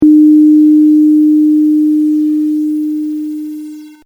Here’s a fixed-frequency sine wave, truncated to seven bits:
7-bit sine tone, no dither
Notice the problems with the non-dithered example, especially in the tail as the signal fades out and fewer bits are used.
7bit_no_dither.mp3